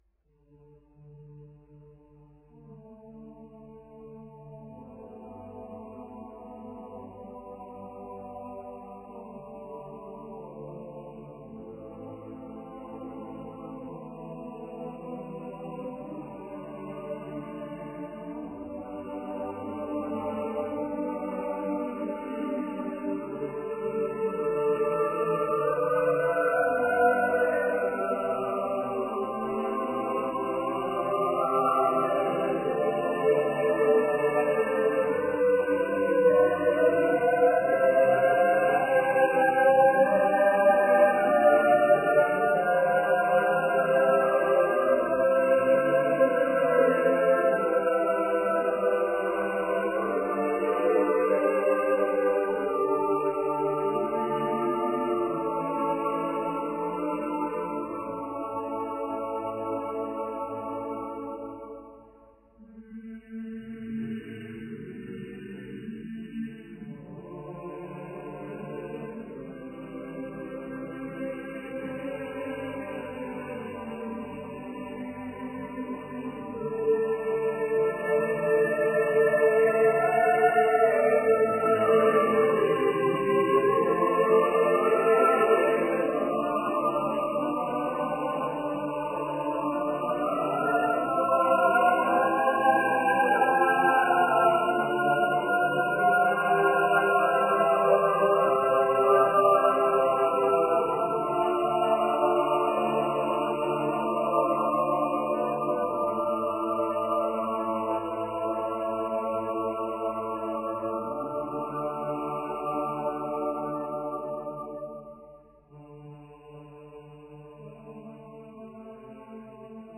Voicing: SSATTBB